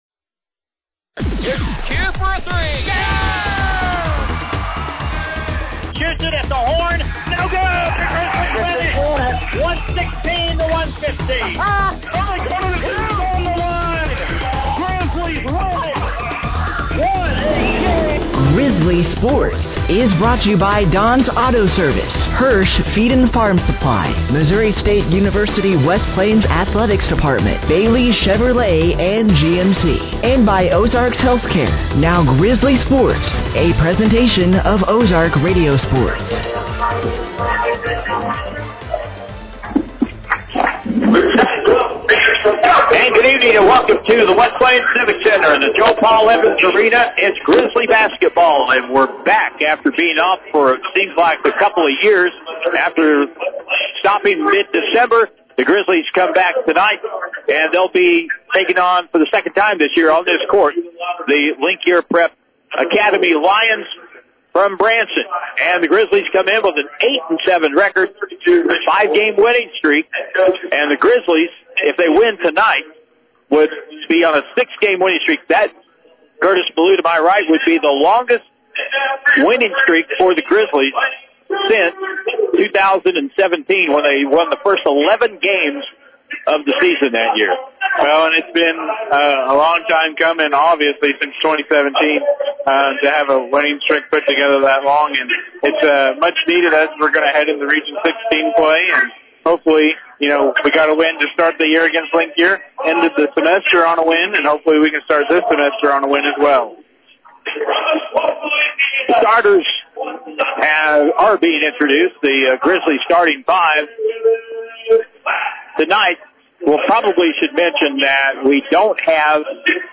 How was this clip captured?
And they did as they blew the Link Year Lions from Branson, Missouri out of the water with an impressive Final score of 131-52. The Game taking place on the home court of The Joe Paul Evans Arena @ The West Plains Civic Center.